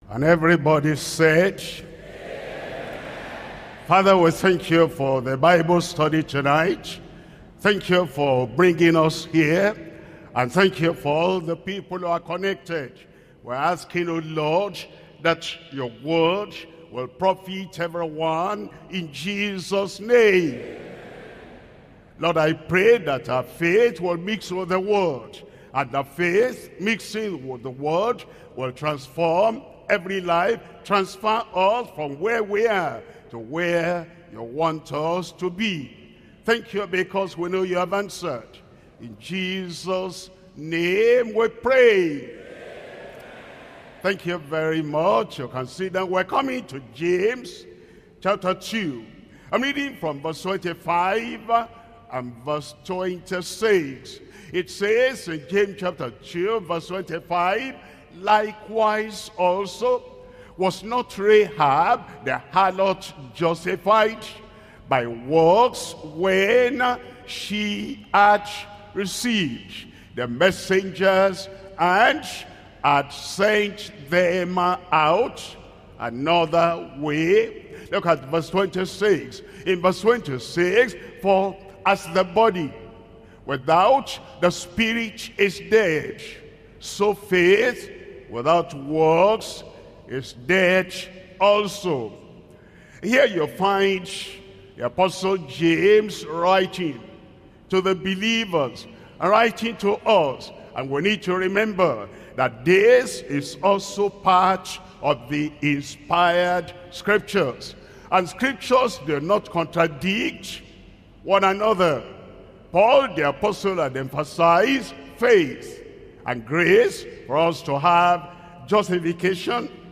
Pastor W.F. Kumuyi
Bible Study